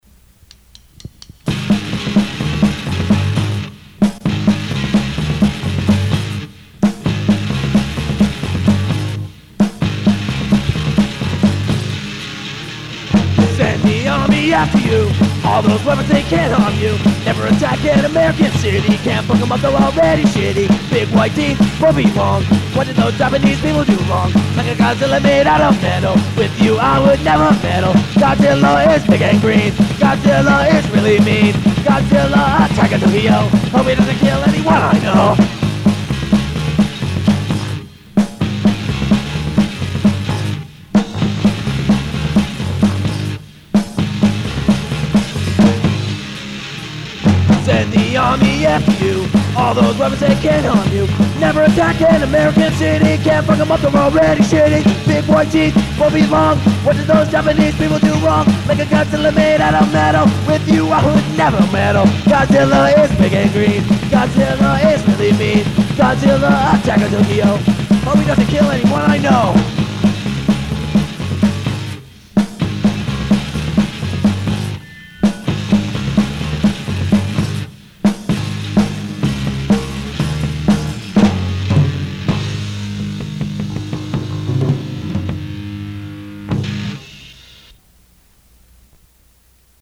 This line up recorded 8 new songs, again on 4-track